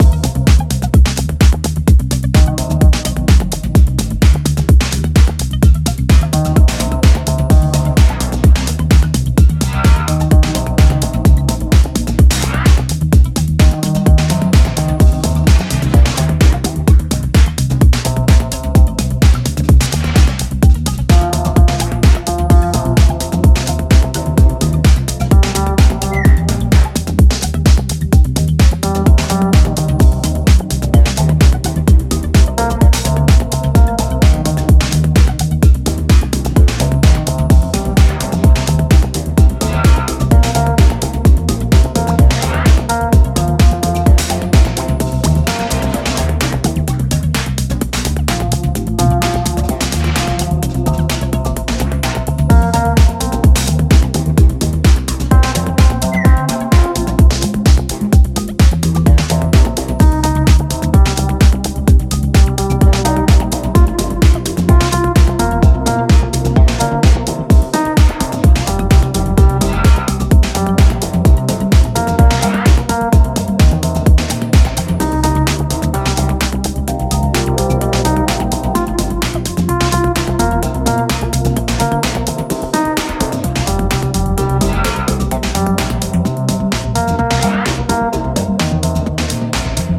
ブルージーなギターのアブストラクト音響加工がアナザーワールド感を演出した